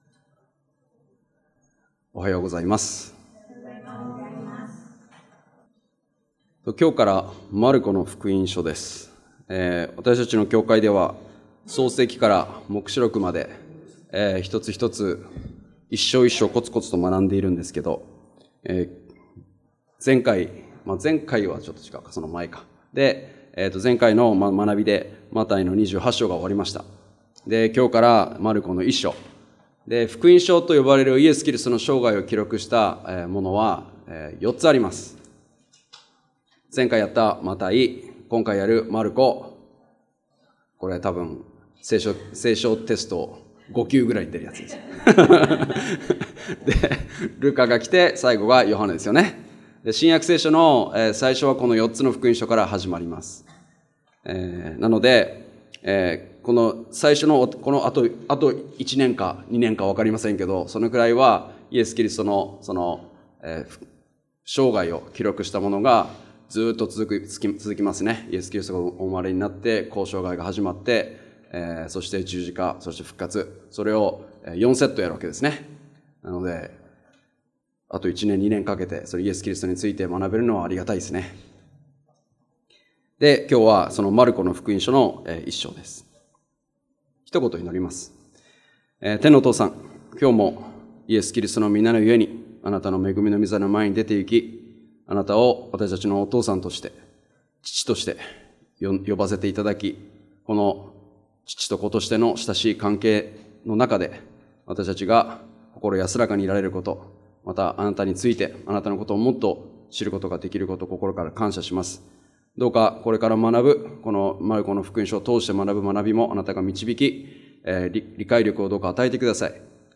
日曜礼拝：マルコの福音書
礼拝やバイブル・スタディ等でのメッセージを聞くことができます。